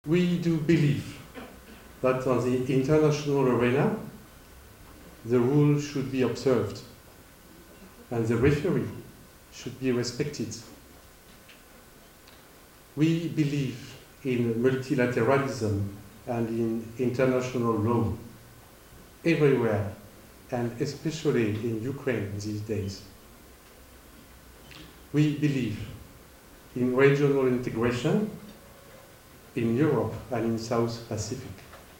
The Ambassador, while addressing the audience last night, shared two inspiring mottos from the French rugby teams.